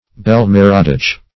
Bel-Merodach - definition of Bel-Merodach - synonyms, pronunciation, spelling from Free Dictionary